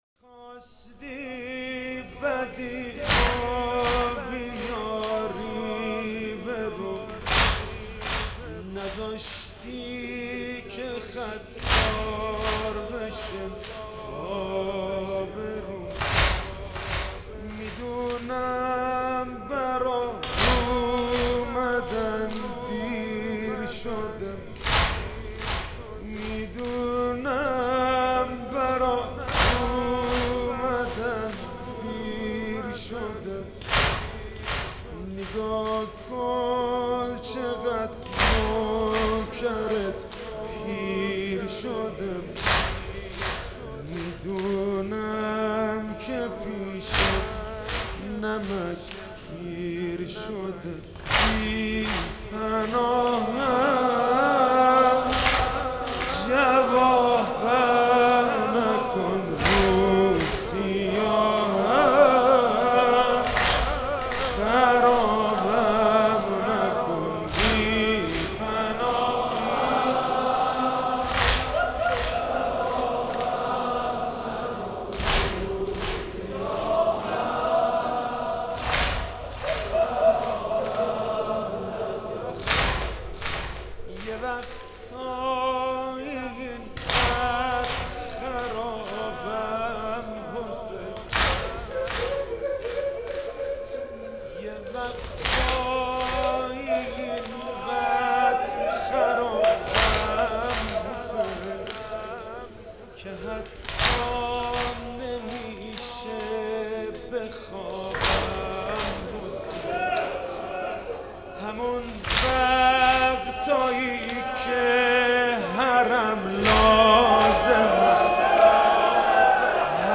محرم 98